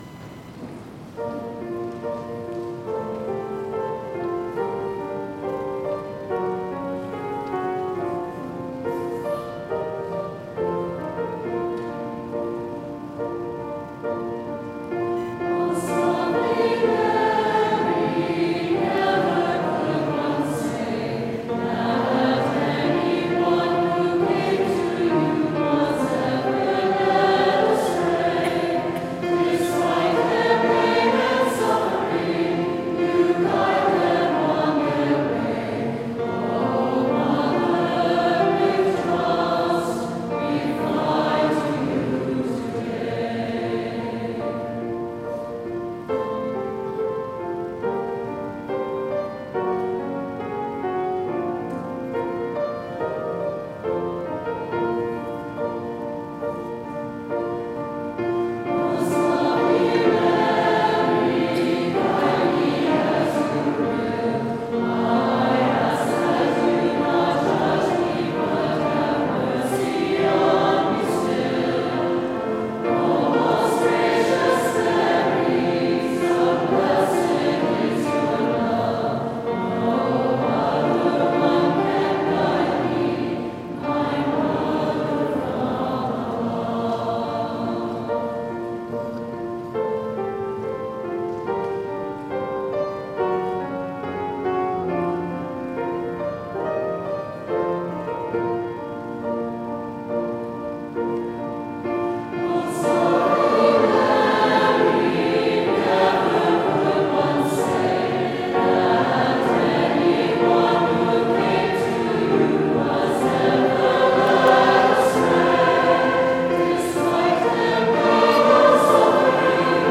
October 13, 2024 - Diocesan Marian Concert